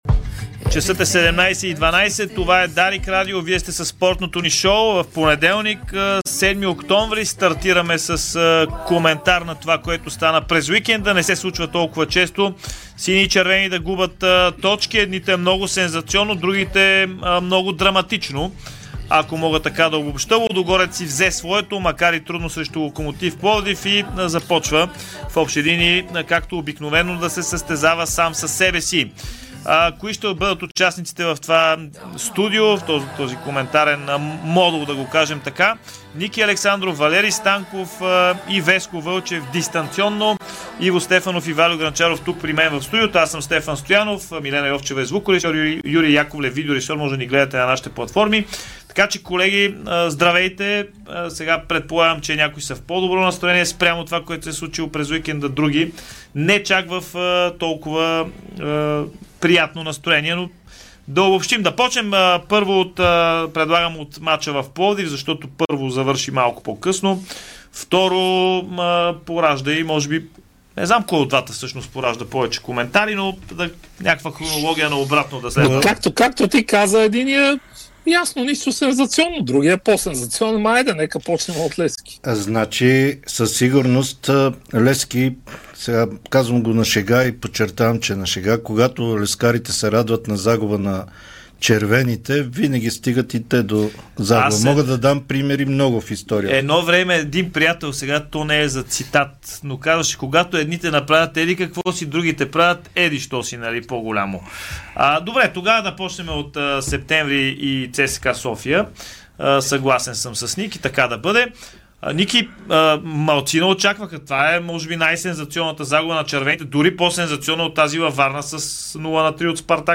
Коментарно студио